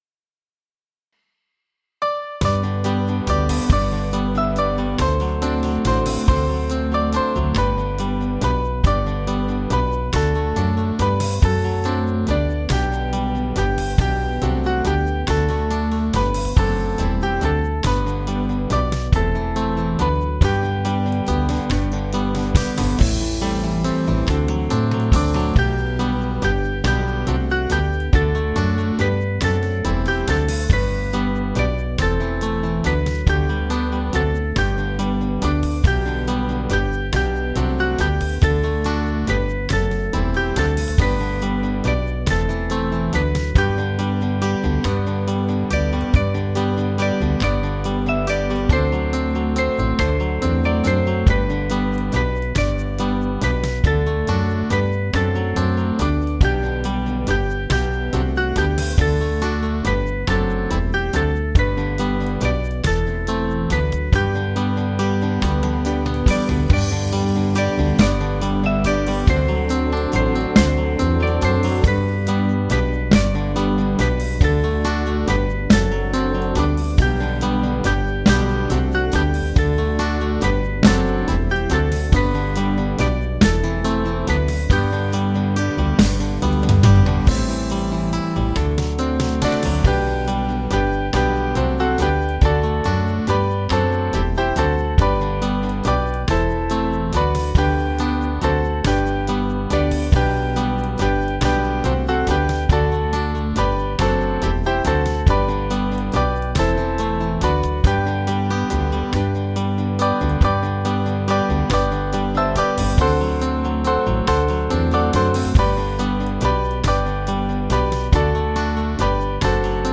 Hymn books
Small Band